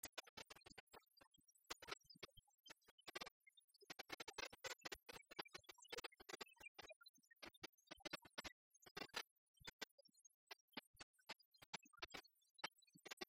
Couplets à danser
branle : courante, maraîchine
Pièce musicale inédite